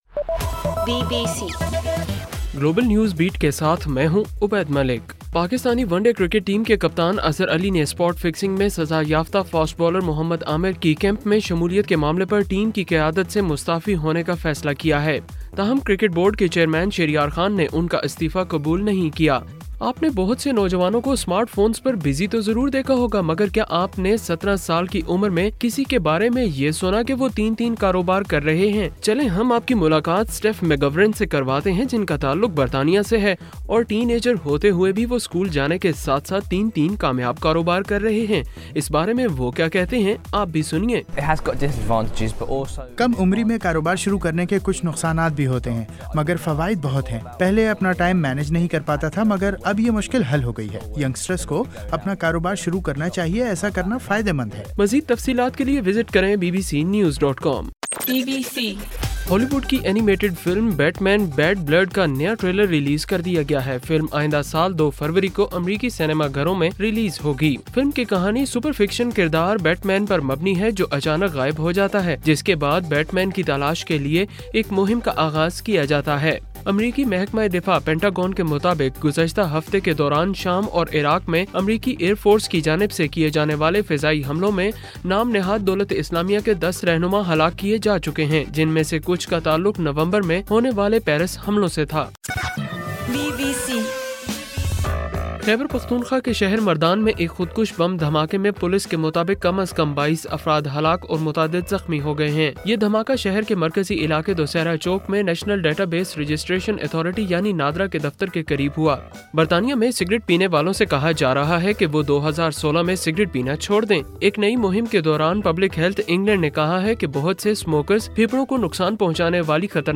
دسمبر 29: رات 11 بجے کا گلوبل نیوز بیٹ بُلیٹن